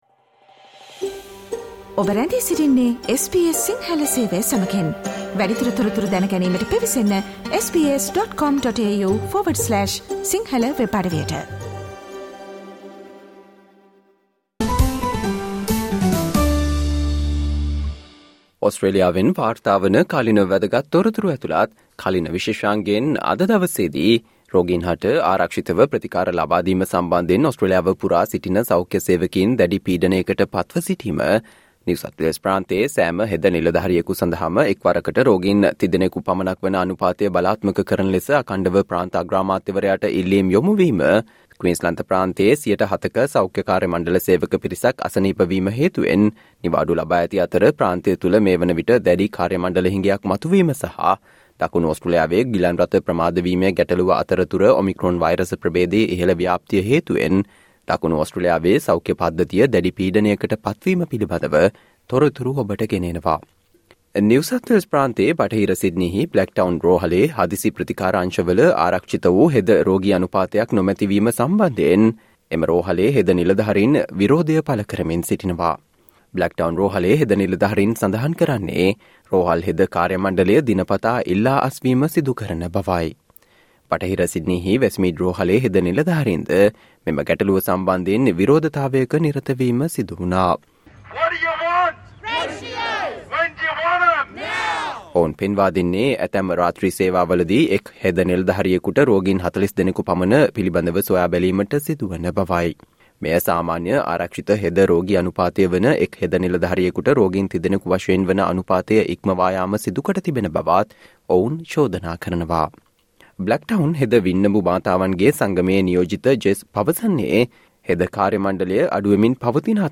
ජූලි 19 වන දා අඟහරුවාදා ප්‍රචාරය වූ SBS සිංහල සේවයේ කාලීන තොරතුරු විශේෂාංගයට සවන්දෙන්න.